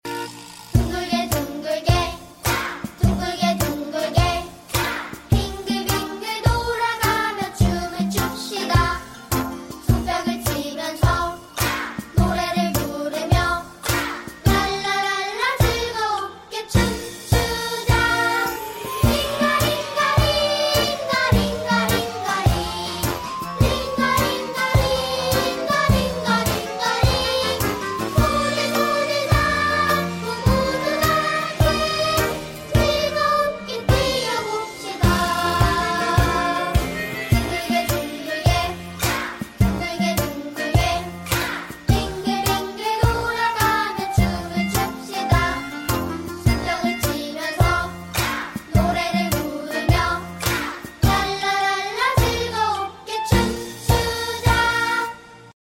asmr